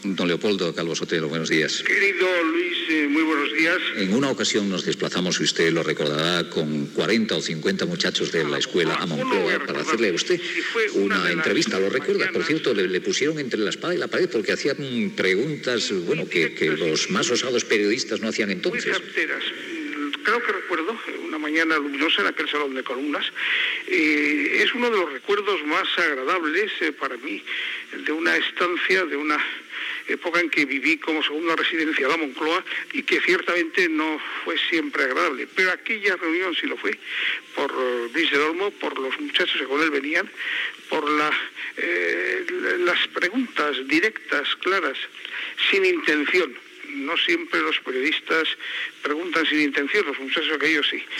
Intervenció telefònica de l'ex president Leopoldo Calvo Sotelo en el 25è aniversari del programa
Info-entreteniment